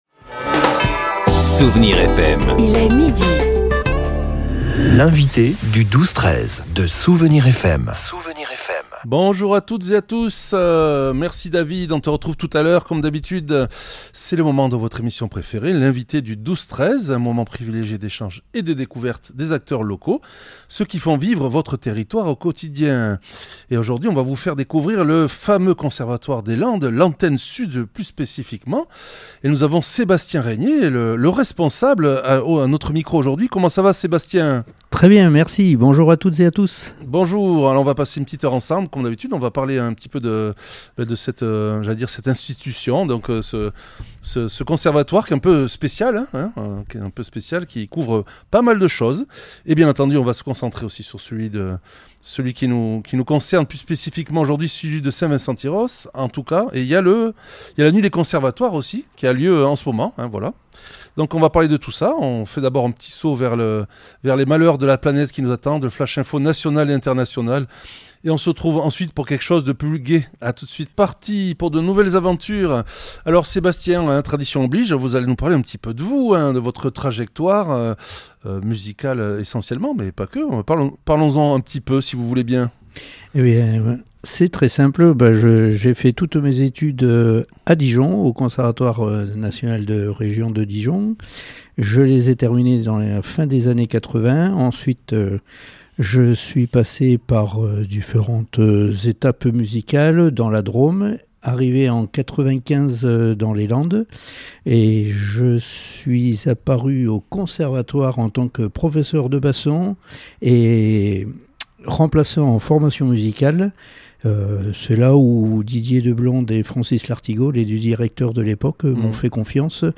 L’invité(e) du 12-13 recevait aujourd’hui